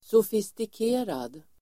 Ladda ner uttalet
sofistikerad adjektiv, sophisticated Uttal: [sofistik'e:rad] Böjningar: sofistikerat, sofistikerade Synonymer: förfinad, raffinerad Definition: förfinad advanced adjektiv, avancerad , långt utvecklad , sofistikerad , extrem